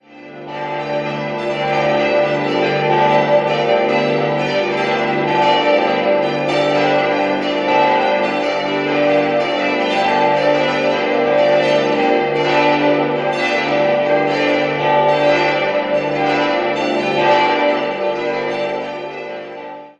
Mai 2010 mit der Einweihungsfeier abgeschlossen wurde. 6-stimmiges erweitertes Westminster-Geläute: d'-g'-a'-h'-d''-e'' Alle Glocken wurden 1967 von Friedrich Wilhelm Schilling in Heidelberg gegossen.